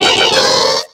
Cri de Lumivole dans Pokémon X et Y.